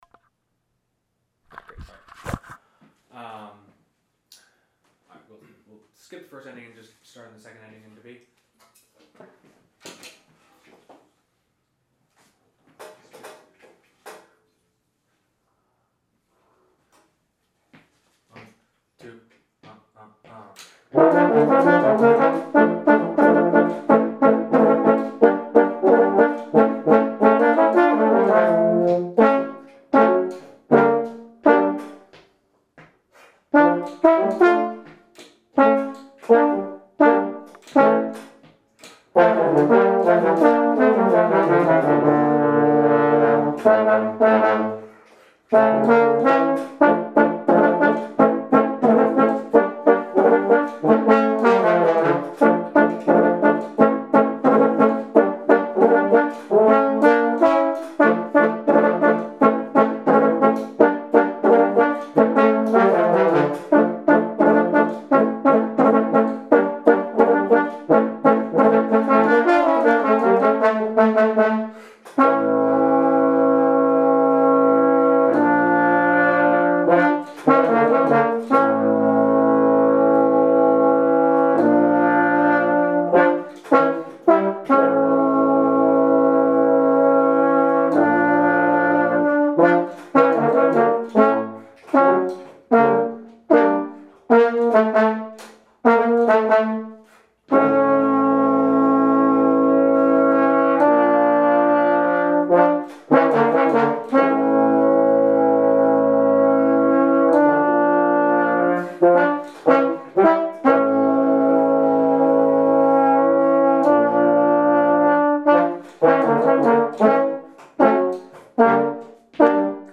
Rehearsal Recordings